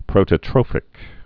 (prōtə-trōfĭk, -trŏfĭk)